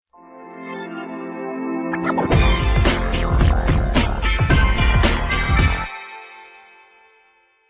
All tracks encoded in mp3 audio lo-fi quality.
christmas pop jingle